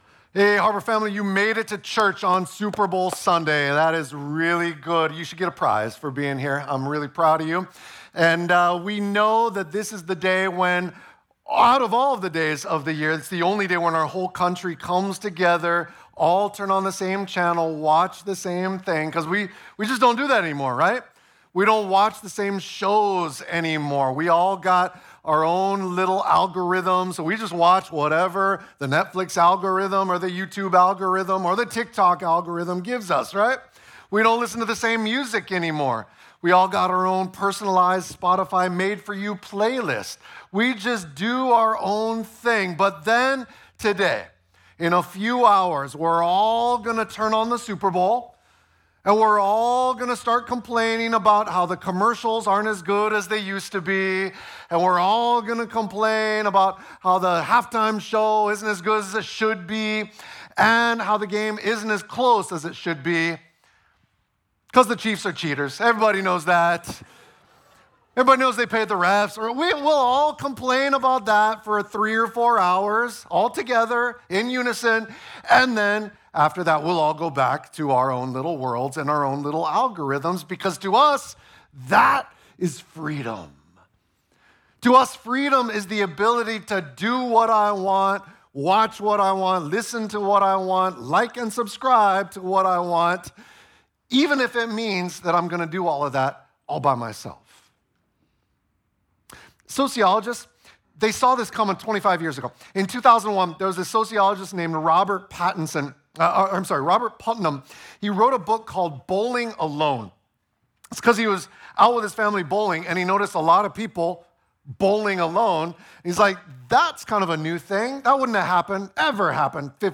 2.9.25-2nd-service-sermon.mp3